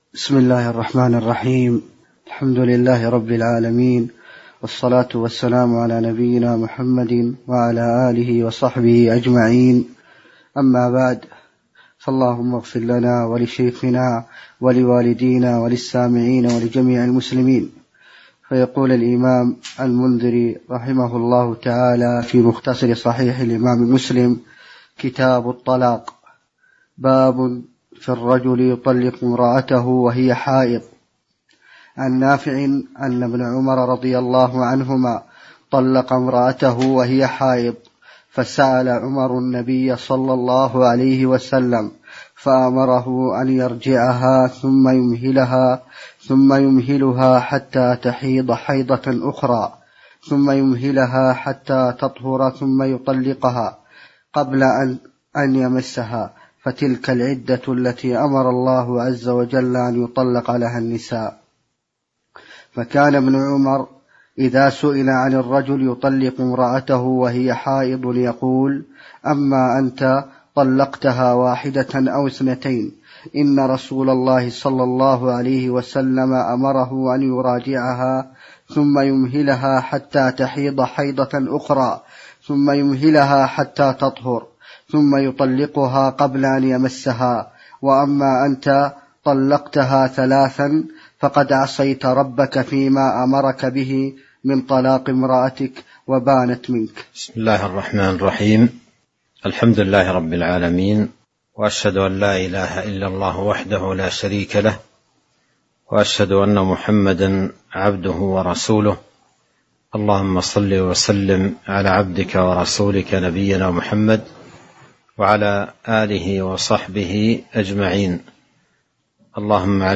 تاريخ النشر ١٤ محرم ١٤٤٣ هـ المكان: المسجد النبوي الشيخ: فضيلة الشيخ عبد الرزاق بن عبد المحسن البدر فضيلة الشيخ عبد الرزاق بن عبد المحسن البدر باب في الرجل يطلق امرأته وهي حائض (01)